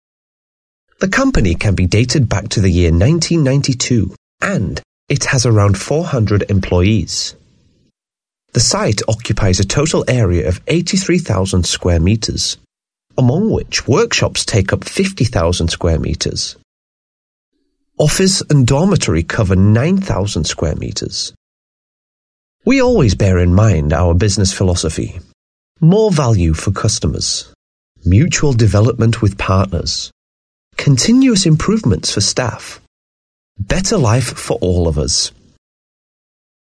英式英文配音